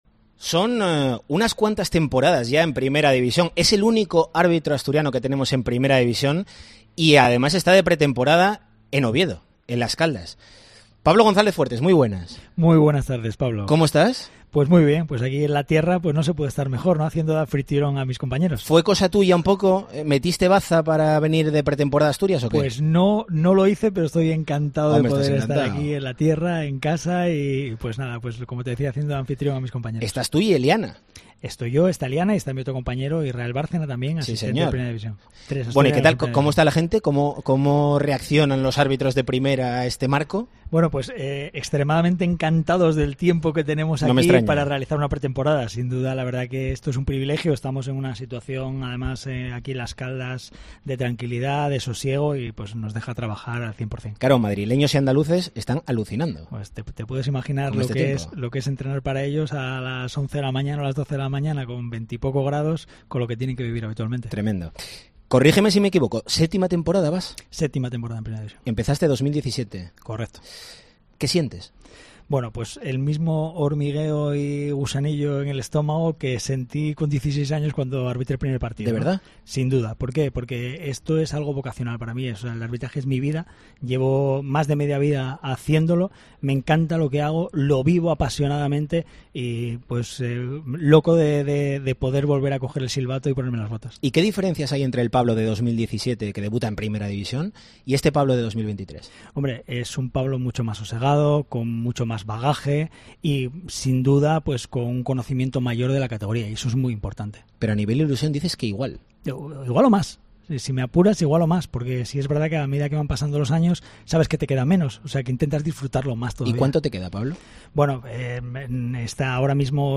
Entrevista en exclusiva en COPE Asturias con el único árbitro asturiano en Primera División, a las puertas de su séptima temporada consecutiva en la élite.